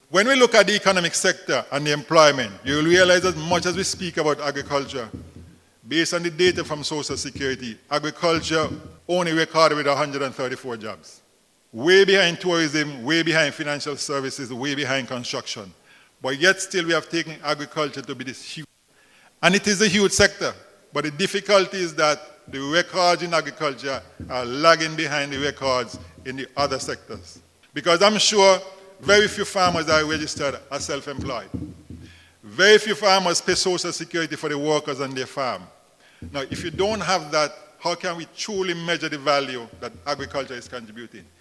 On January 25th, the Nevis Dept. of Agriculture held its 2022 Agenda meeting under the theme “Fostering Economic Growth through Innovation”.
During the occasion, Permanent Secretary in the NIA’s Ministry of Finance and guest speaker at the meeting, Mr. Colin Dore, outlined the impacts the Agricultural sector is making on the economy as a whole.